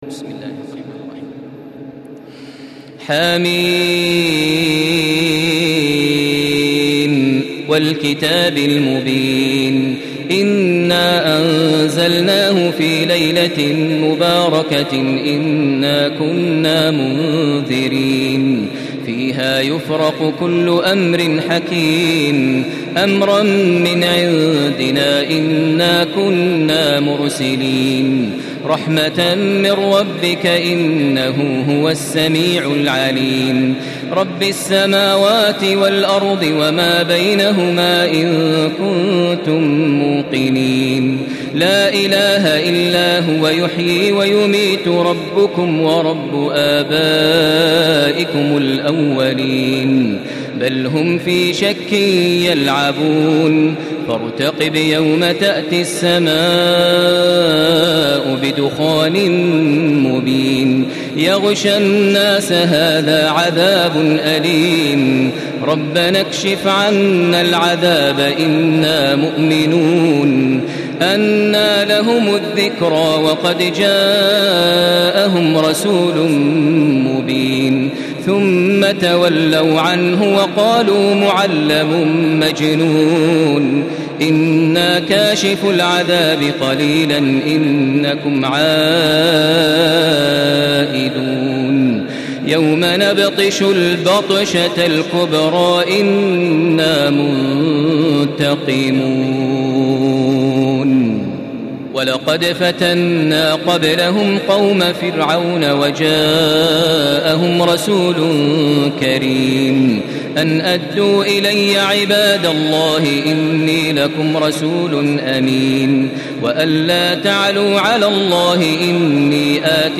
Surah Ad-Dukhan MP3 in the Voice of Makkah Taraweeh 1435 in Hafs Narration
Murattal